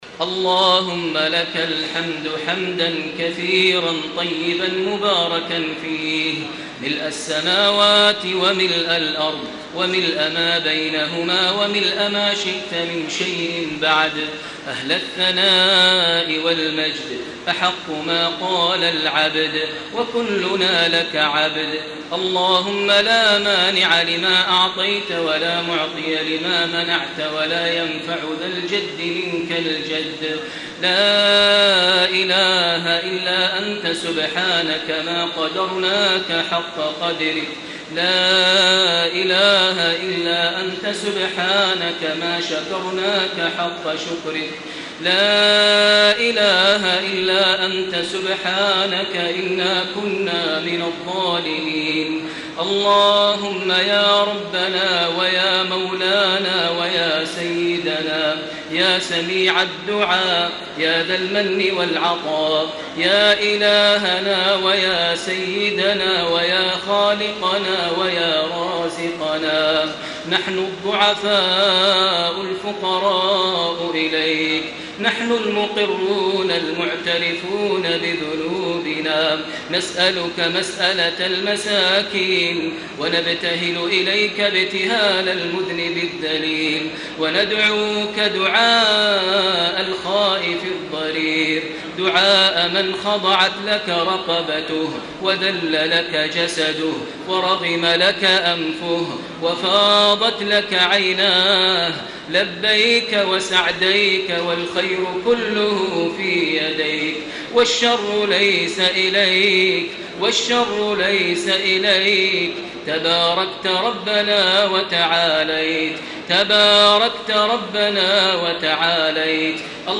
دعاء خاشع ومؤثر ليلة 6 رمضان لعام 1434هـ.
تسجيل لدعاء خاشع ومؤثر في ليلة 6 رمضان 1434هـ من صلاة القيام أو التراويح.